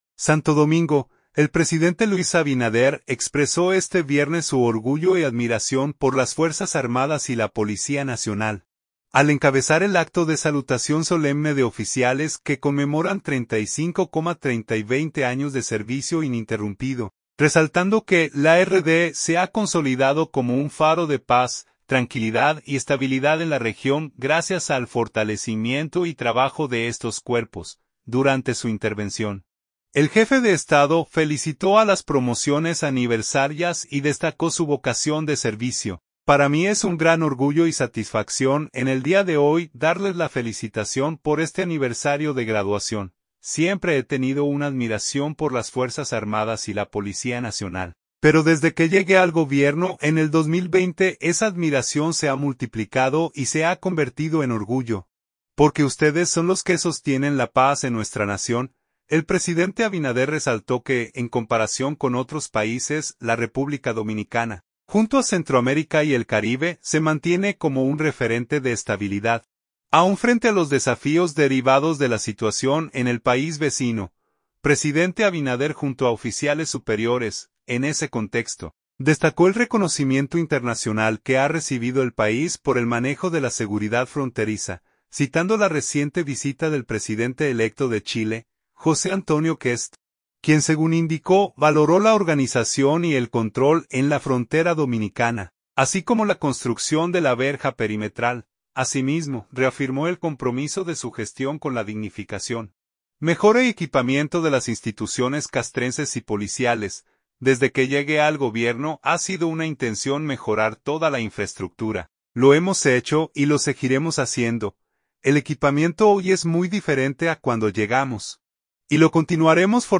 Santo Domingo. – El presidente Luis Abinader, expresó este viernes su orgullo y admiración por las Fuerzas Armadas y la Policía Nacional, al encabezar el acto de salutación solemne de oficiales que conmemoran 35, 30, 25 y 20 años de servicio ininterrumpido, resaltando que la RD se ha consolidado como “un faro de paz, tranquilidad y estabilidad” en la región gracias al fortalecimiento y trabajo de estos cuerpos.